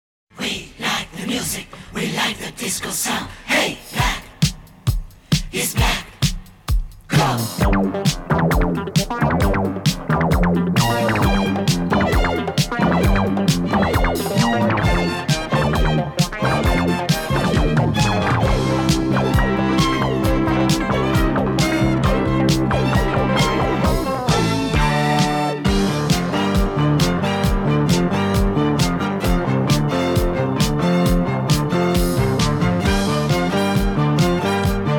Жанр: Хип-Хоп / Рэп / Рок / R&B / Соул